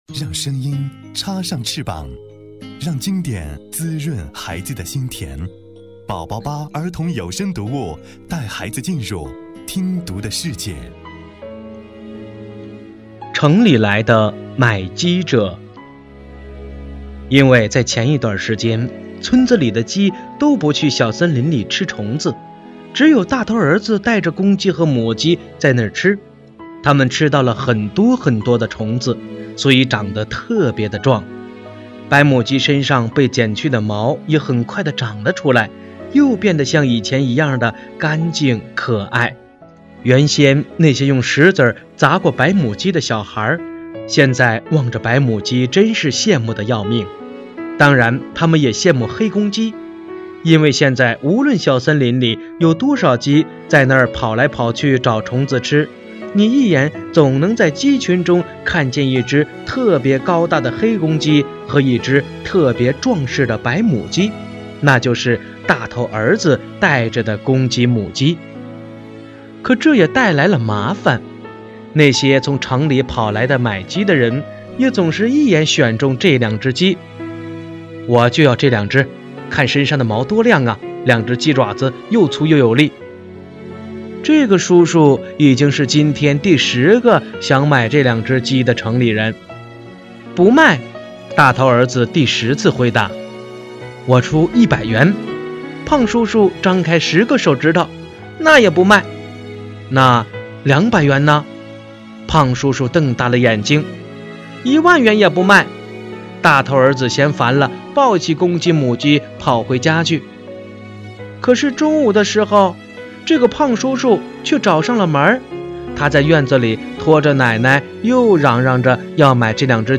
首页>mp3 > 儿童故事 > 07城里来的买鸡者（大头儿子和公鸡母鸡）